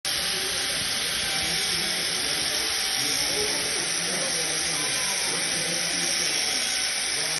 grinder with small squares in action.mp4